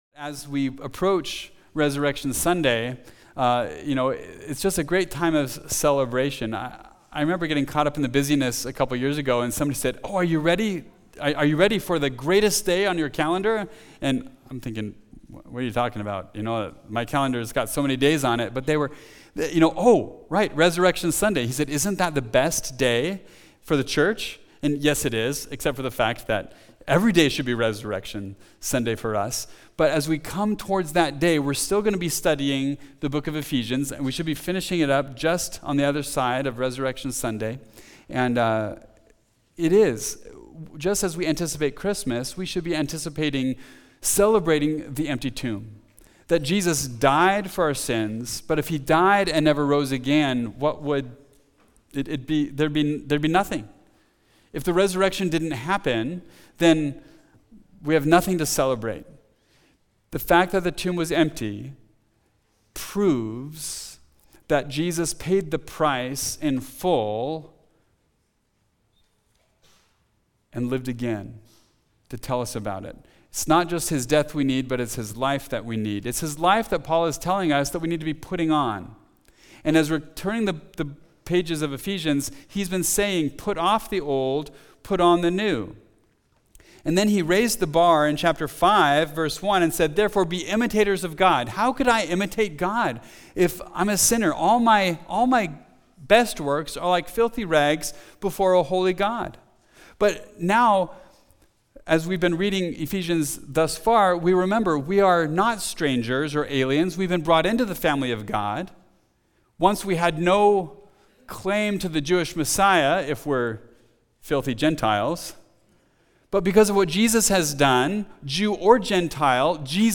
Walk In Wisdom – Mountain View Baptist Church